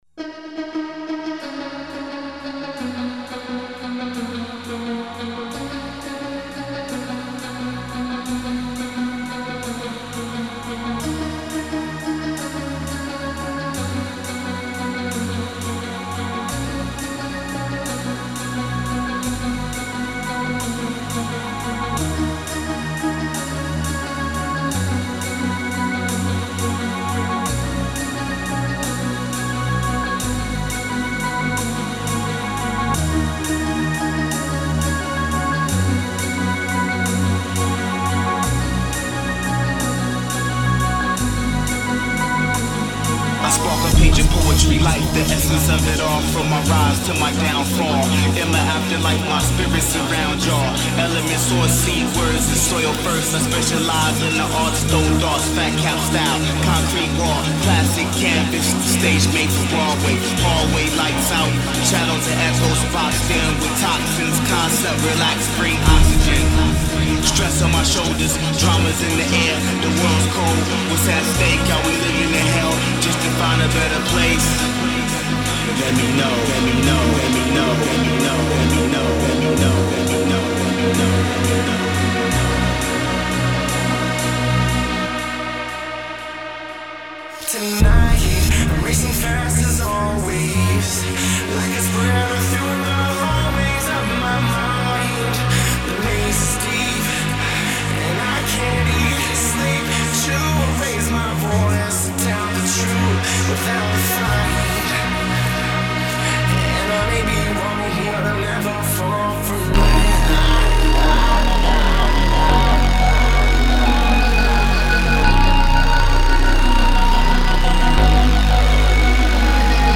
Жанр: Drum&Bass